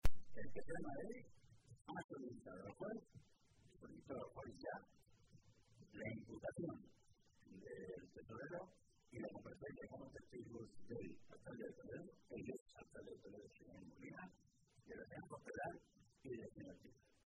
Jesús Fernández Vaquero, Secretario de Organización del PSCM-PSOE
Cortes de audio de la rueda de prensa